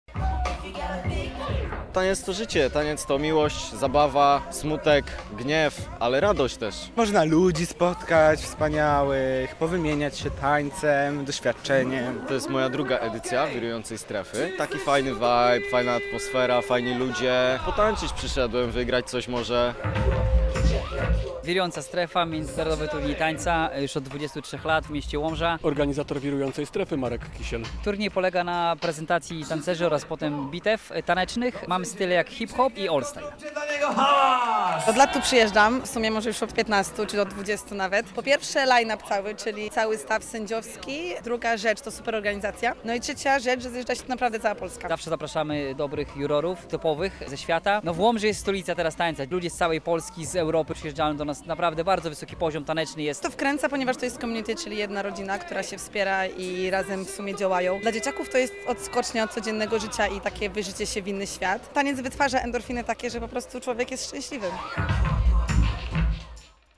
Trwa Wirująca Strefa - relacja